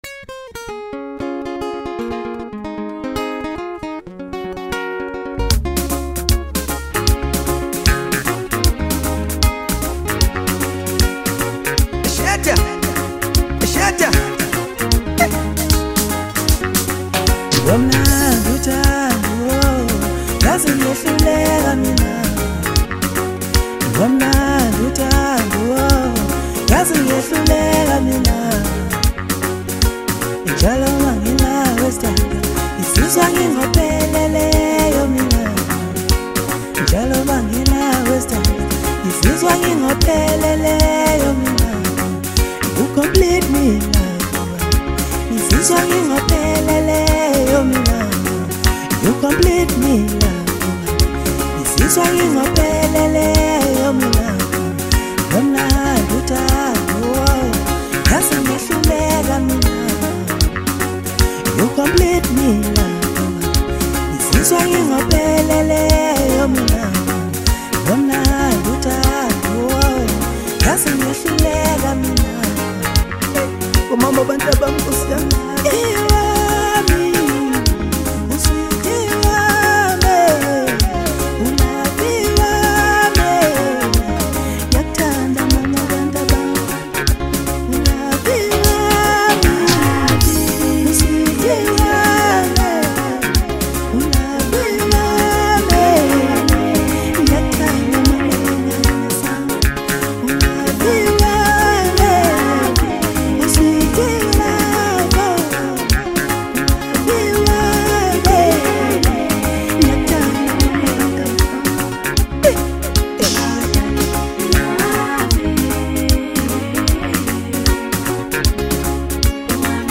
blending rhythm, soul, storytelling, and modern sound